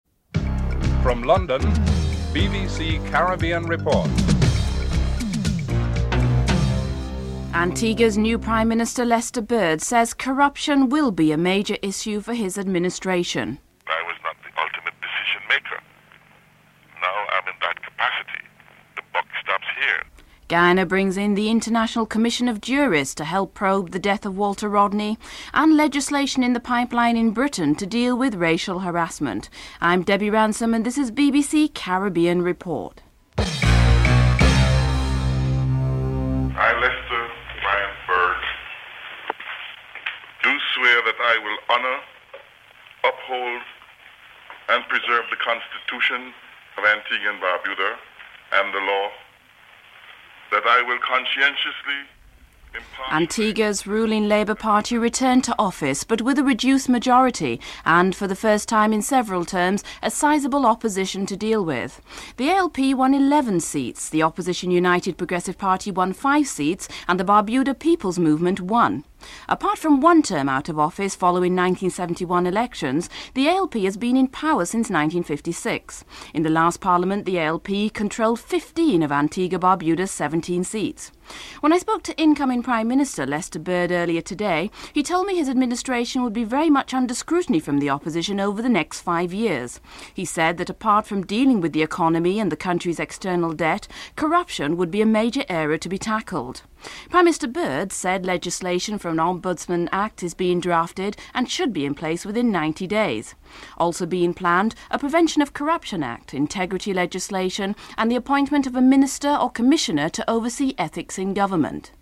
2. Antigua’s Labour Party return to office back for the first time with reduced majority. Lester Bird is interviewed in this segment.
Bernard dos Santos, the Attorney General of Guyana is interviewed (08:37-10:56)
6. Wrap up and theme music (14:21-14:52)